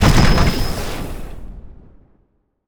sci-fi_explosion_04.wav